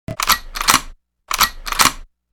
GunCock02.wav